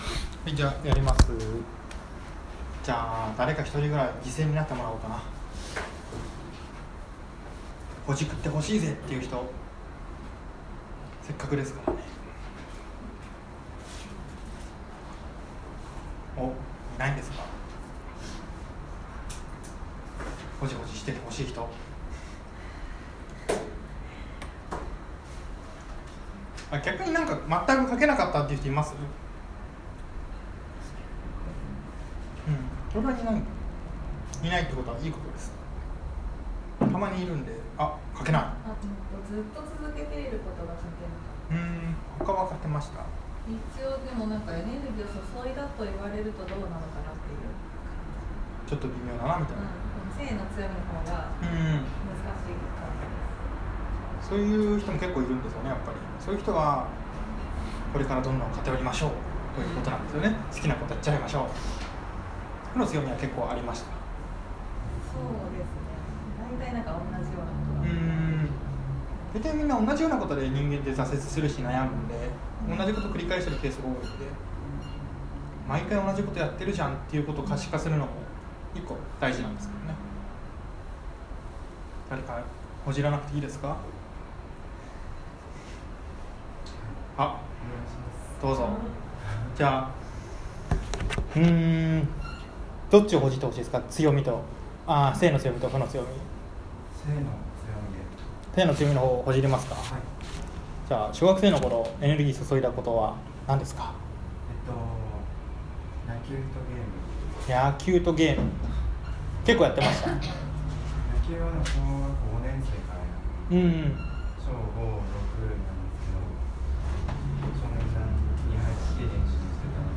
強み・個性の覚醒セミナー2018Part.2.m4a